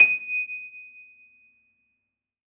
53h-pno23-D5.wav